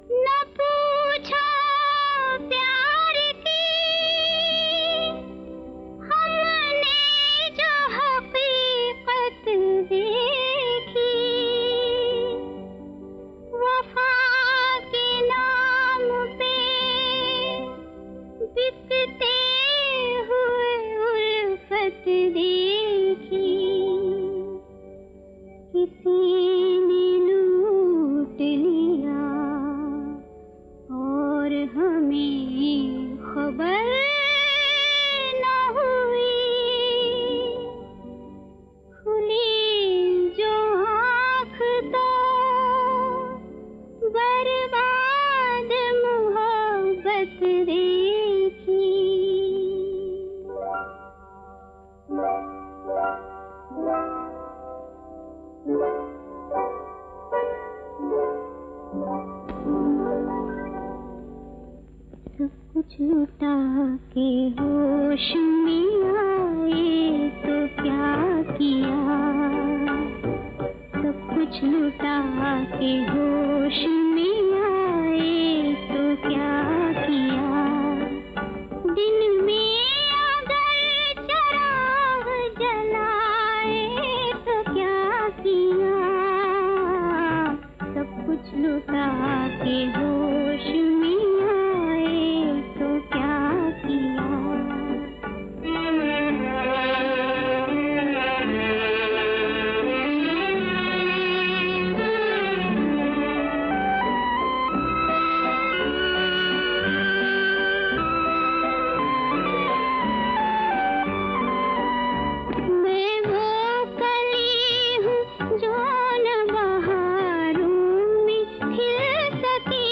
हे गाणे चित्रपटात तीन प्रसंगी वेगवेगळ्या मूडमध्ये गायले आहे.